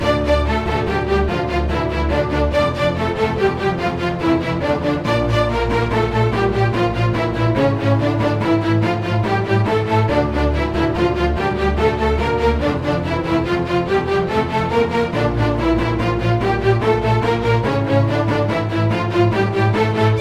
标签： 95 bpm Orchestral Loops Strings Loops 3.40 MB wav Key : Unknown
声道立体声